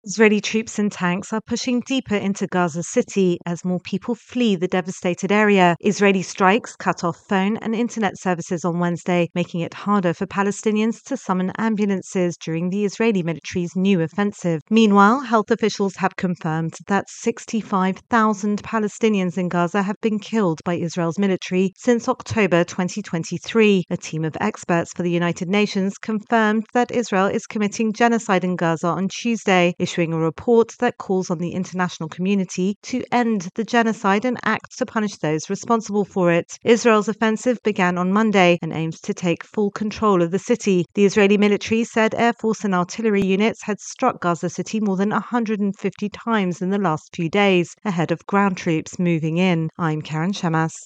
reports on the latest Israeli strikes on Gaza.